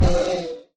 mob / horse / zombie / hit1.ogg